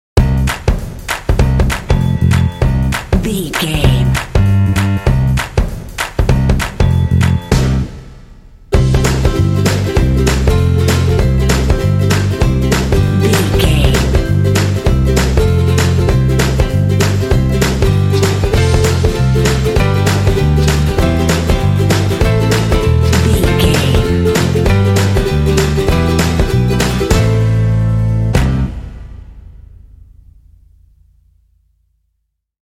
Ionian/Major
bouncy
bright
driving
energetic
festive
joyful
acoustic guitar
piano
bass guitar
drums
rock
contemporary underscore
alternative rock
indie